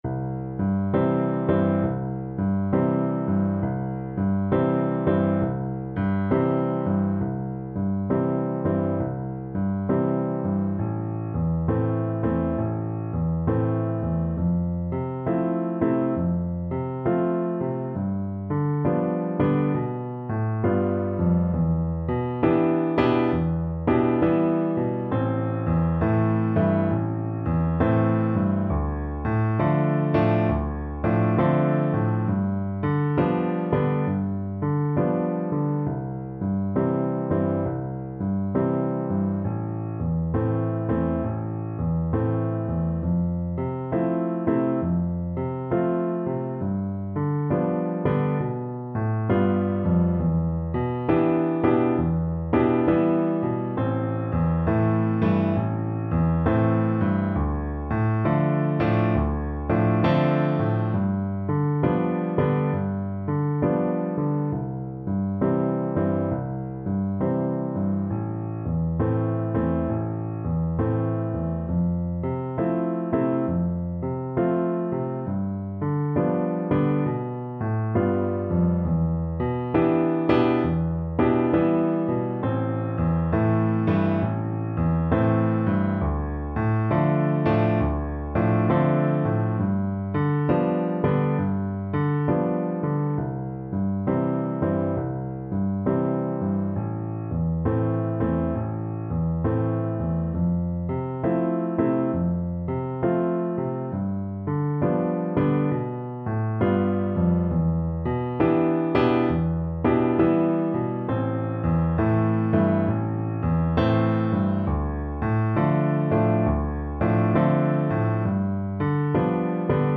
C major (Sounding Pitch) (View more C major Music for Bassoon )